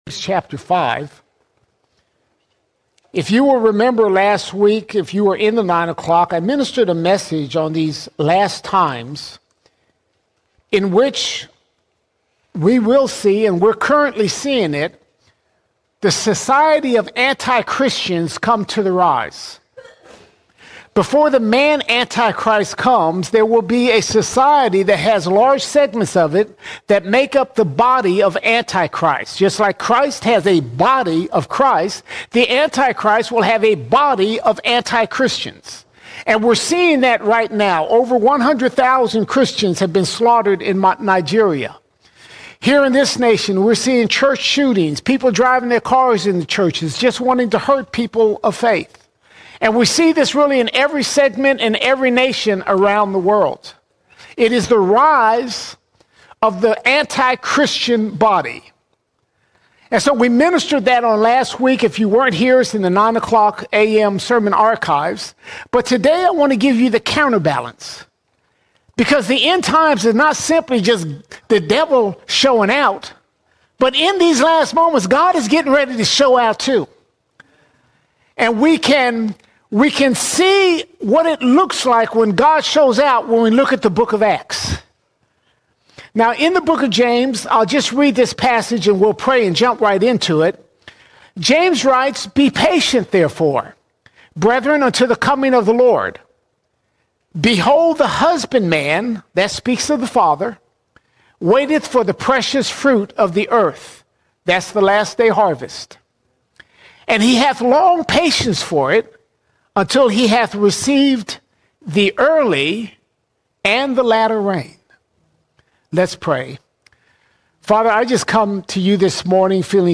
6 October 2025 Series: Sunday Sermons All Sermons The Outpour The Outpour God has promised an end-time outpouring of His Spirit, the latter rain.